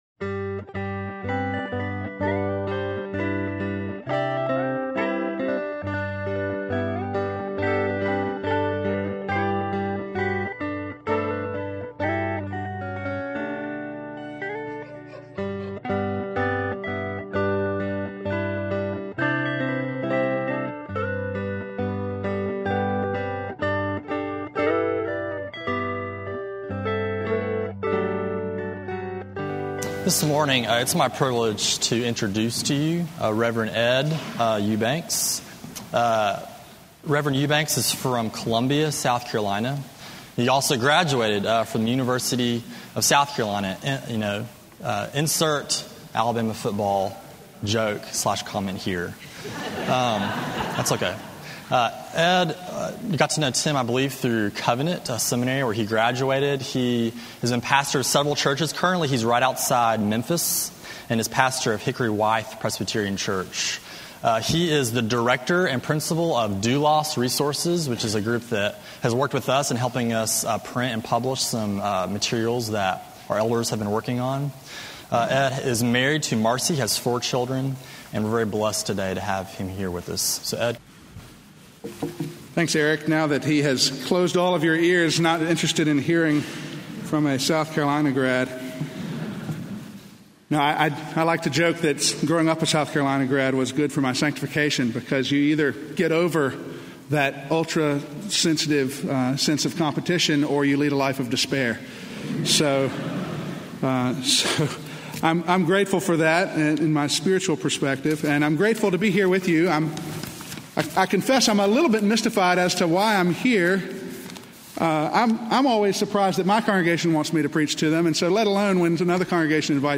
Sermon on Acts 3:1-24 from October 17